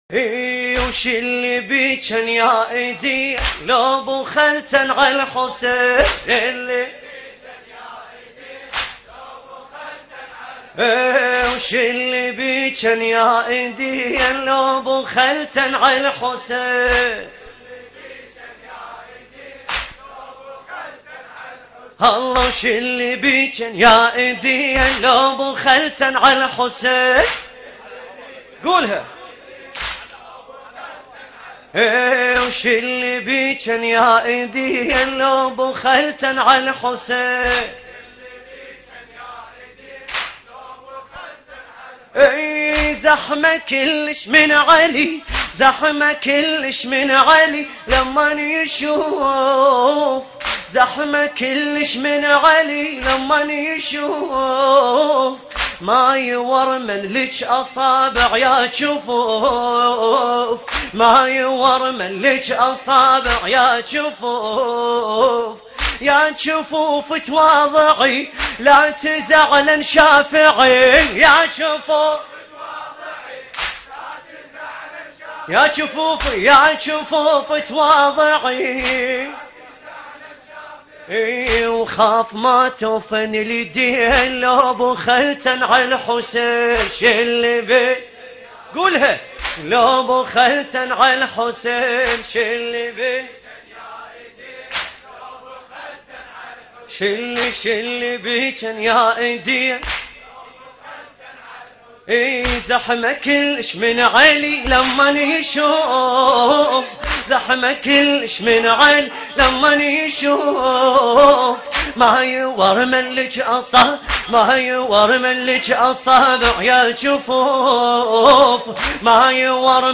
لطميات متفرقة